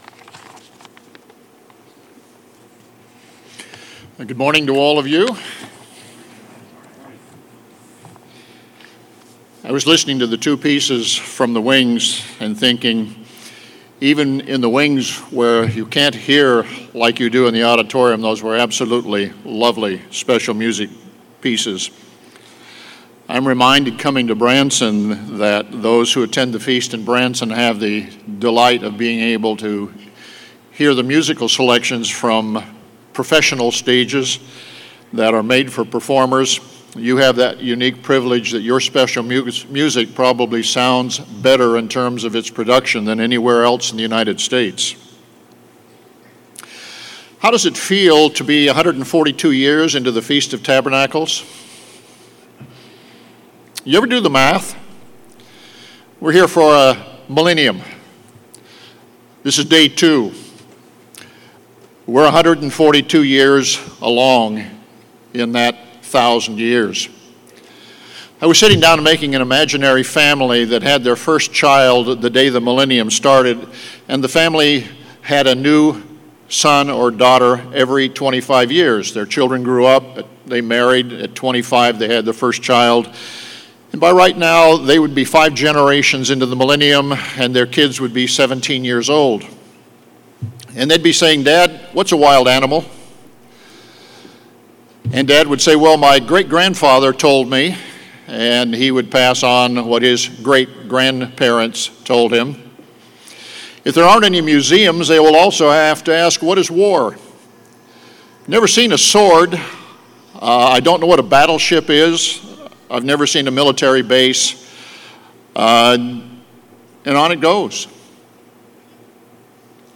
This sermon was given at the Branson, Missouri 2021 Feast site.